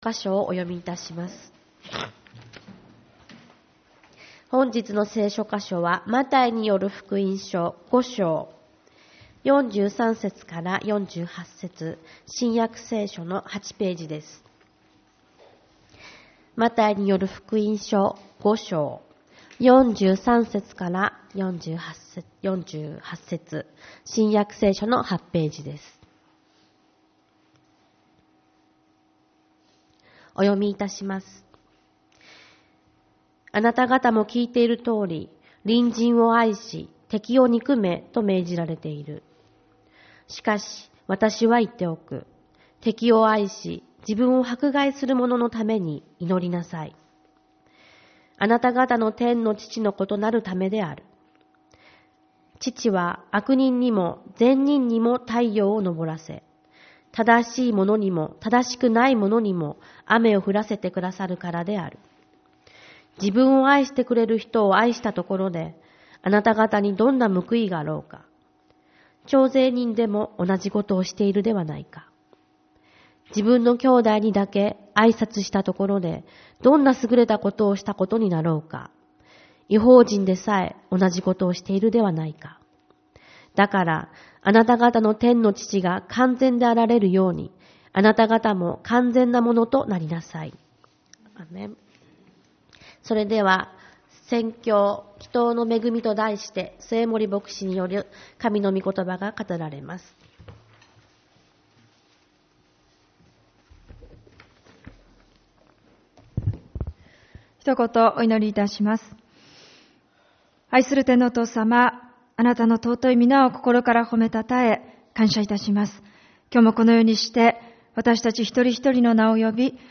主日礼拝 「祈祷の恵み」 マタイによる福音書5:43-48